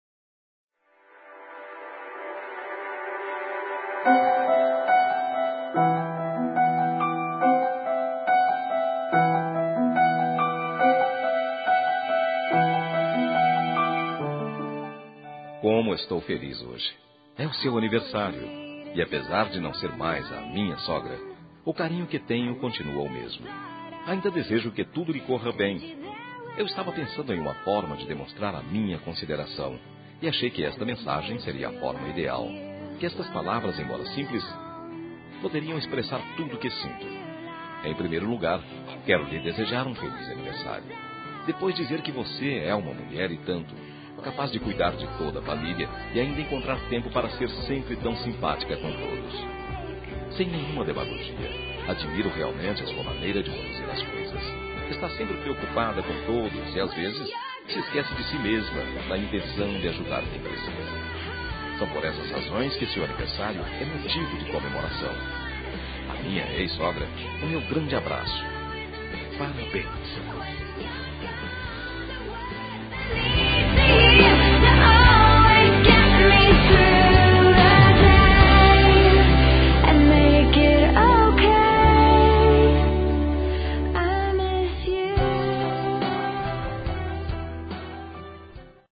Telemensagem Aniversário de Sogra – Voz Masculina – Cód: 202169 – Ex-Sogra